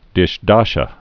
(dĭsh-däshə)